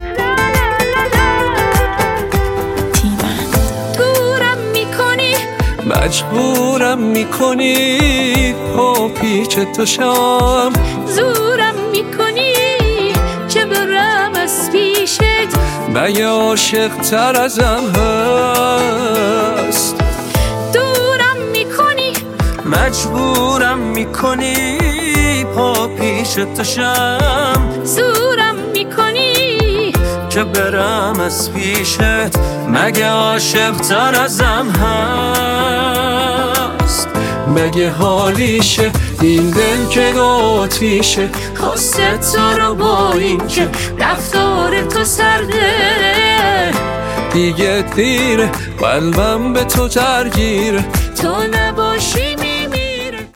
(AI)